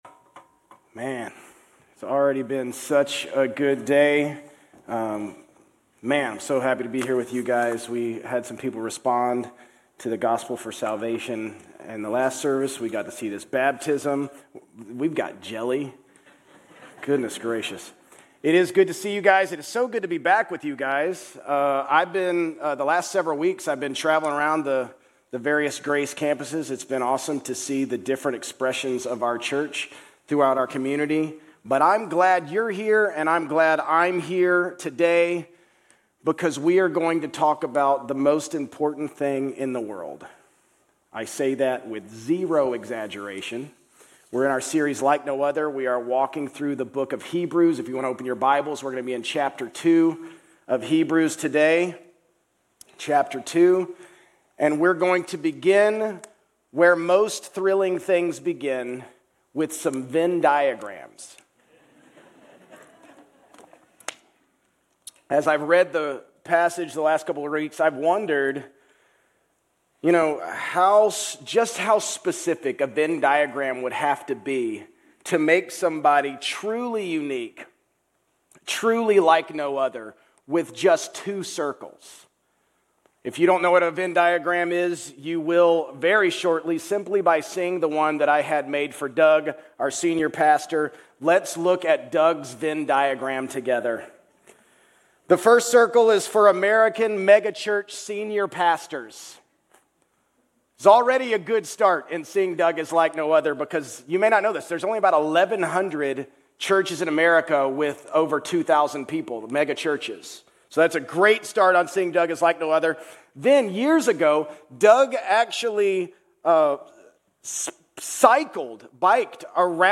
Grace Community Church Old Jacksonville Campus Sermons 9_28 Old Jacksonville Campus Sep 29 2025 | 00:38:07 1x 00:00 / 00:38:07 Subscribe Share RSS Feed Share Link Embed